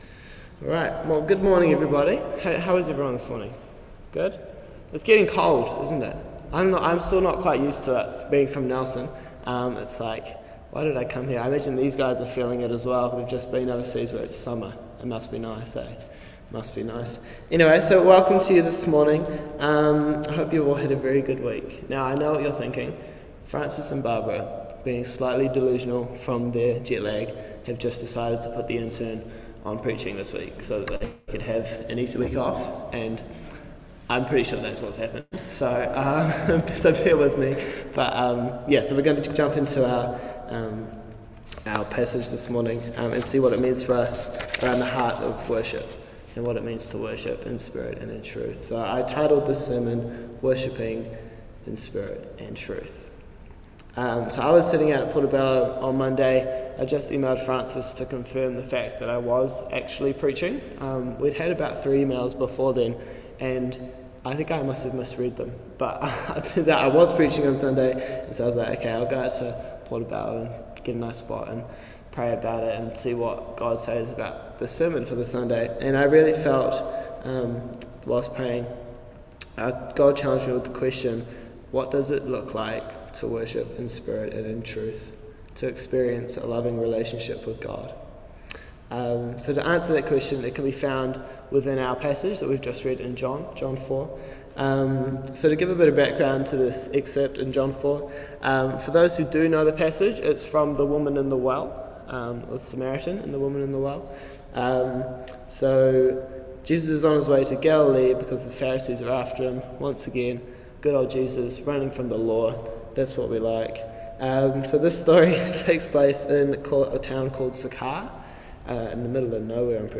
Passage: John 4:21-26, Psalms 103:1-8 Service Type: Holy Communion Topics